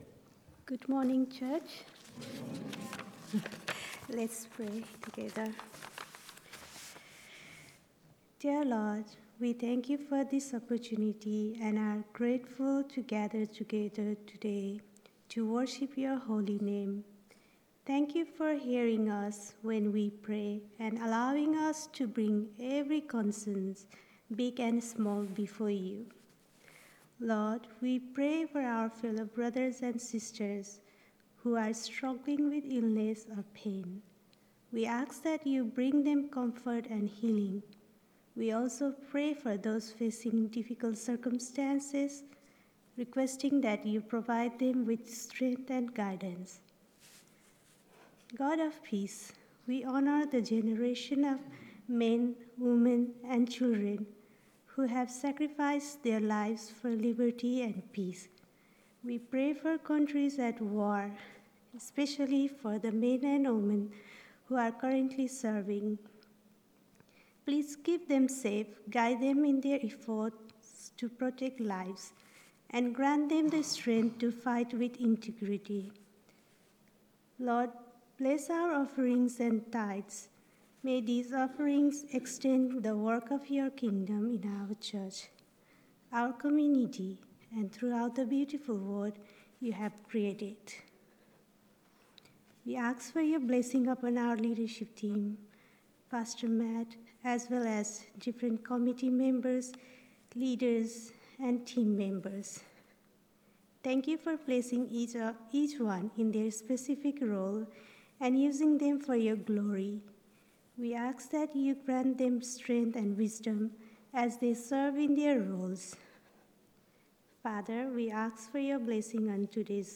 Introductory Prayers
Prayers.mp3